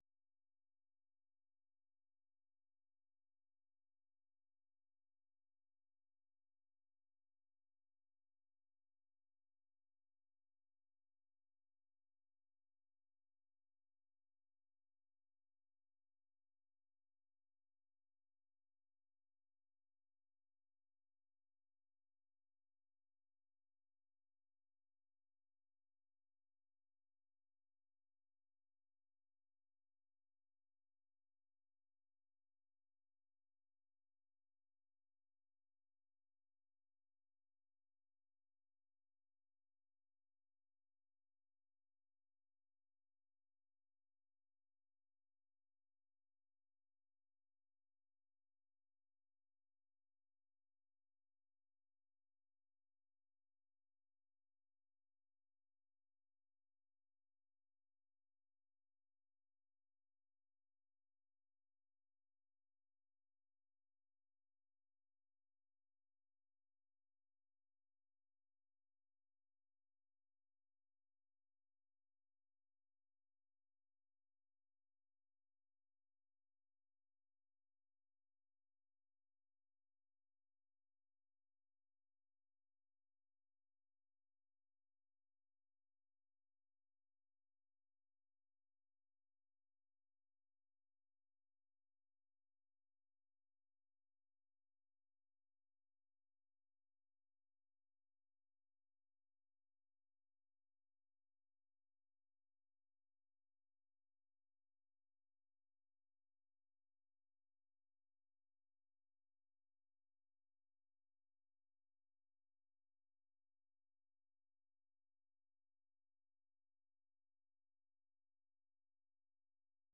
အပြည်ပြည်ဆိုင်ရာ စံတော်ချိန် ၂၃၃၀ ၊ မြန်မာစံတော်ချိန် နံနက် ၆ နာရီကနေ ၇ နာရီထိ (၁) နာရီကြာ ထုတ်လွှင့်နေတဲ့ ဒီ ရေဒီယိုအစီအစဉ်မှာ မြန်မာ၊ ဒေသတွင်းနဲ့ နိုင်ငံတကာ သတင်းနဲ့ သတင်းဆောင်းပါးတွေ သီတင်းပတ်စဉ်ကဏ္ဍတွေကို နားဆင်နိုင်ပါတယ်။